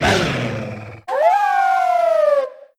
Grito de Lycanroc.ogg
) Categoría:Gritos de Pokémon de la séptima generación No puedes sobrescribir este archivo.
Grito_de_Lycanroc.ogg.mp3